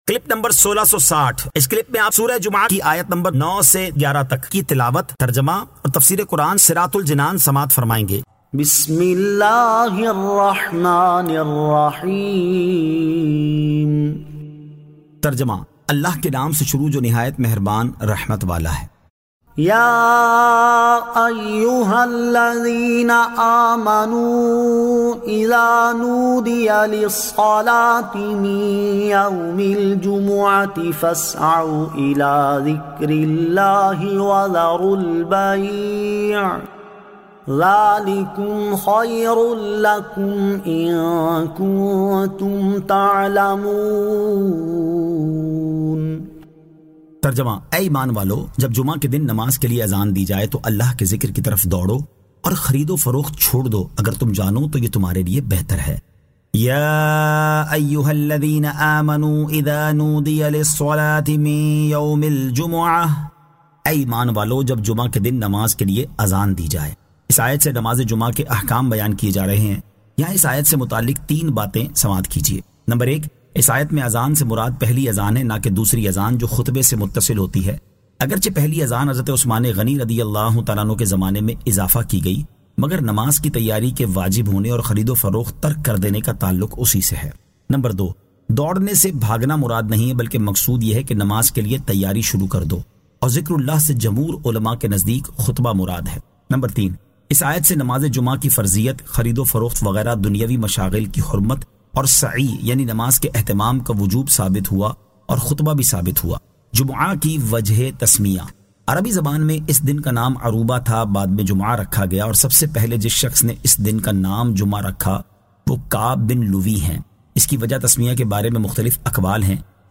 Surah Al-Jumu'ah 09 To 11 Tilawat , Tarjama , Tafseer